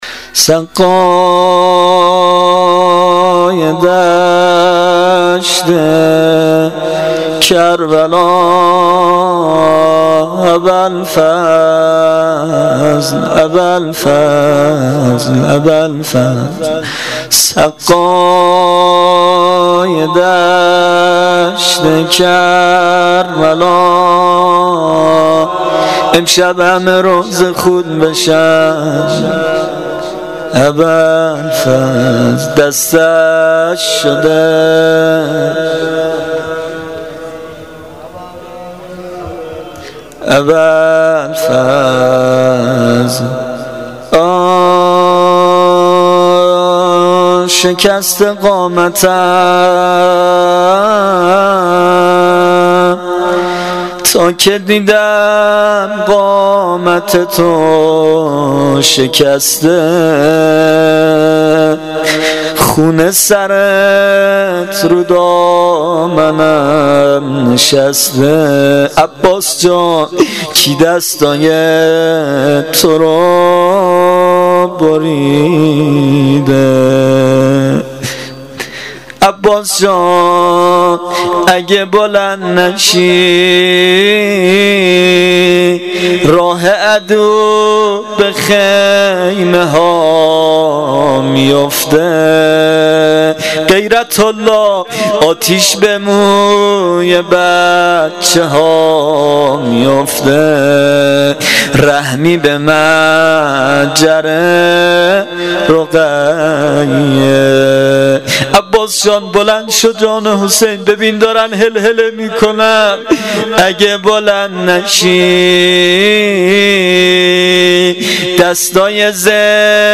زمزمه
روضه